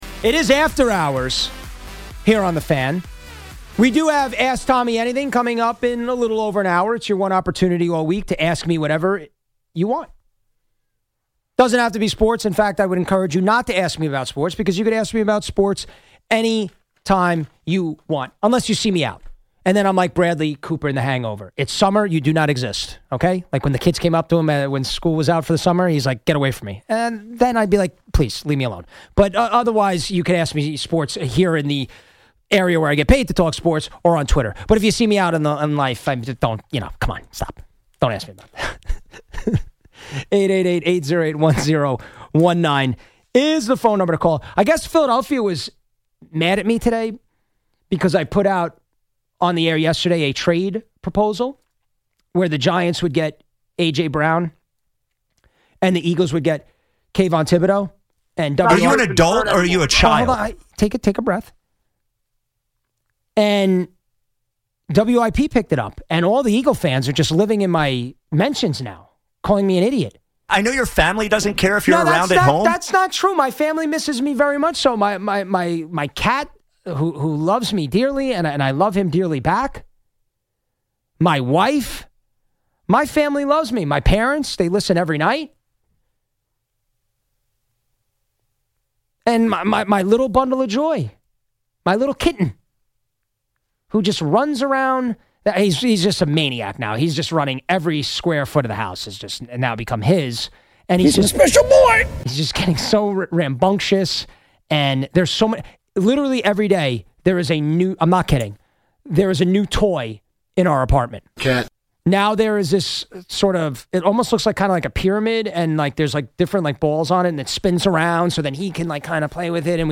talks with a Yankee fan caller who wrote a poem